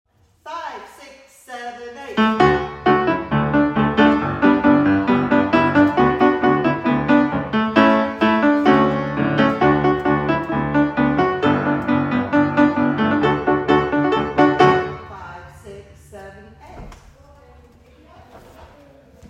Audition Song Backing Track: (